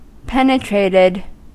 Ääntäminen
Ääntäminen US Haettu sana löytyi näillä lähdekielillä: englanti Käännöksiä ei löytynyt valitulle kohdekielelle. Penetrated on sanan penetrate partisiipin perfekti.